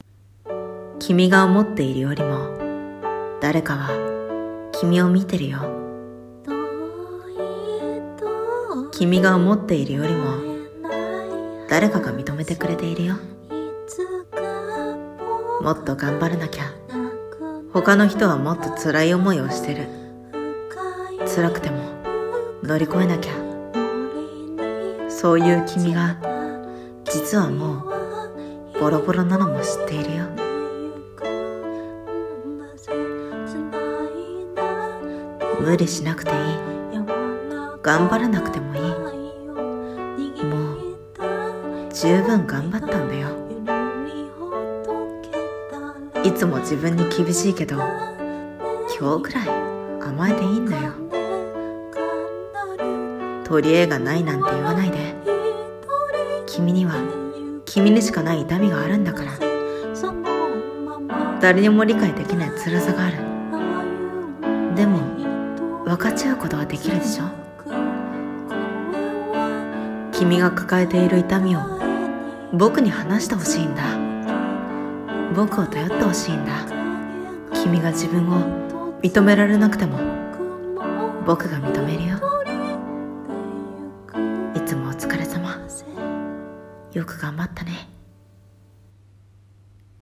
朗読台本】がんばる君へ